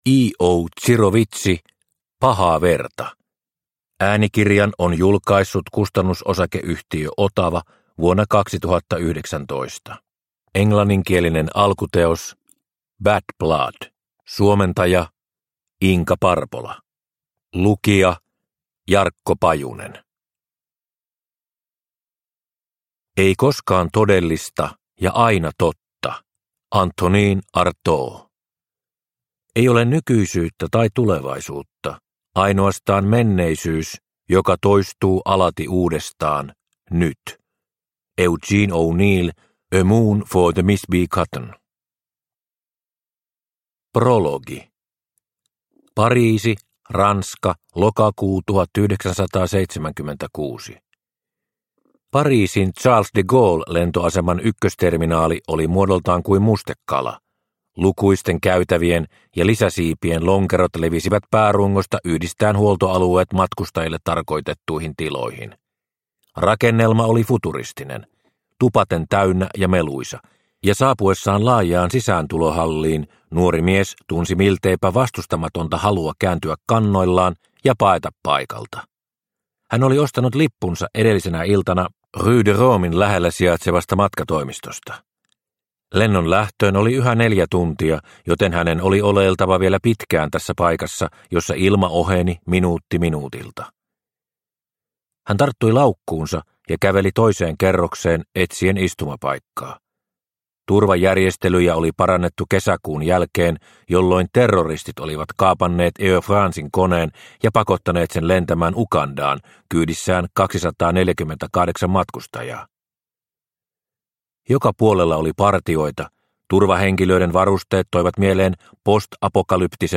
Pahaa verta – Ljudbok – Laddas ner